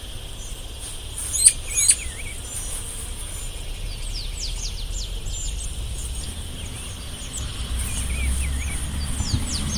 Juan Chiviro (Cyclarhis gujanensis)
Nombre en inglés: Rufous-browed Peppershrike
Condición: Silvestre
Certeza: Vocalización Grabada